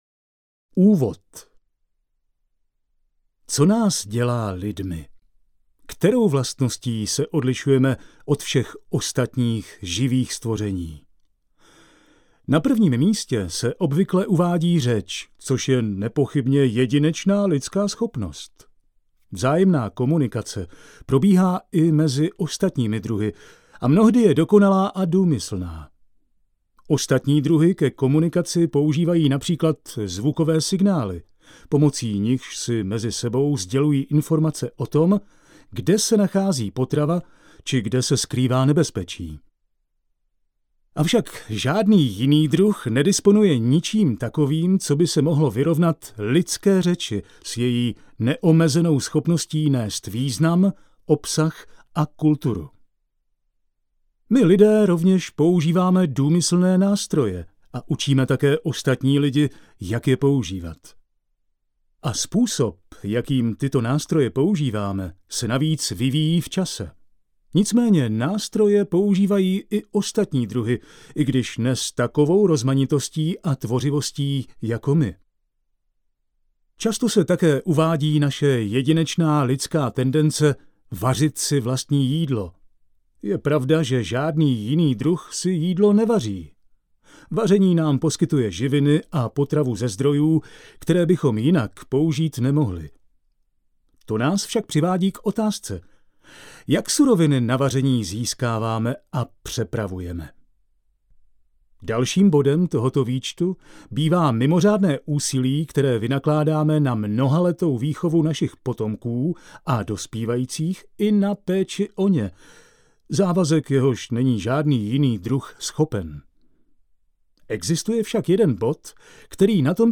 Audiokniha Proč chodíme